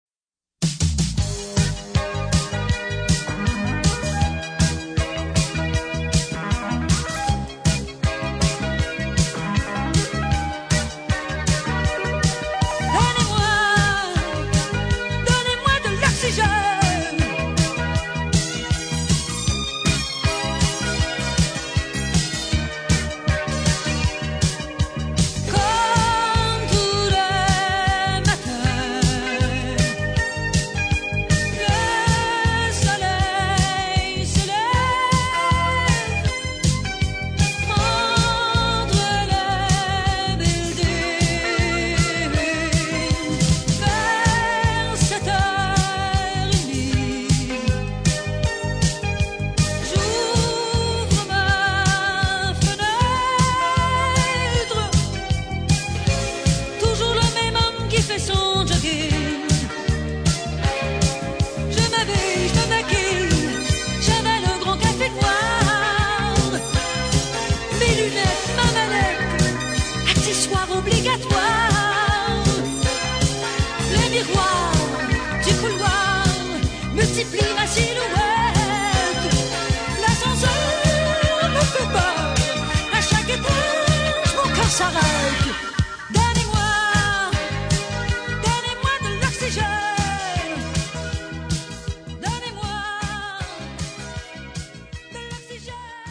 Jeudi le 27 août 2009, nous nous réunissions au Théâtre de la Dame de Cœur pour célébrer le 30e anniversaire de l’AREQ du Vieux -Longueuil.
L’équipe du comité de la condition de la femme, nommée pour l’organisation de cette fête, avait choisi de souligner en chansons cet événement.